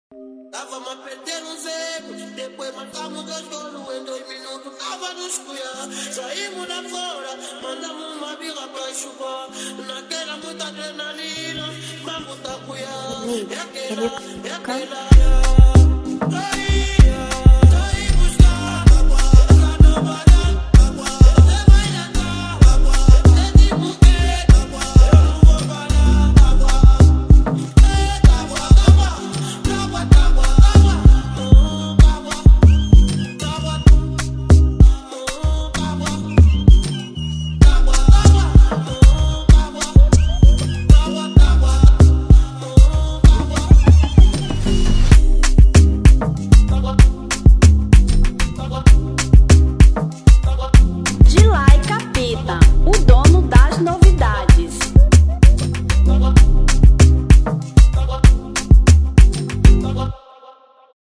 Remix 2025